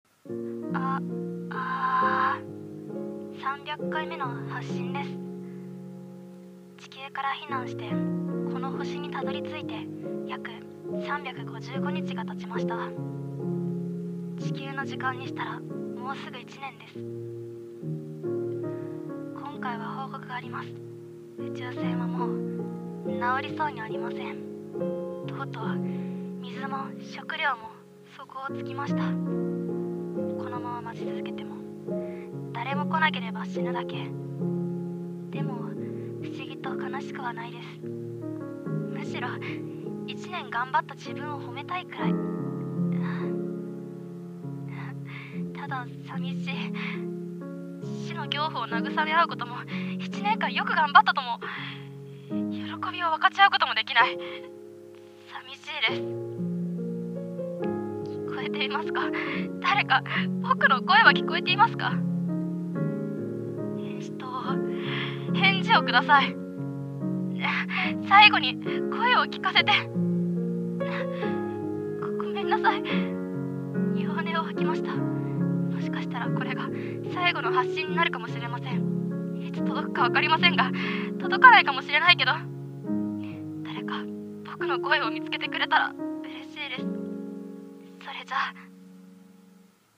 声劇「星の孤独」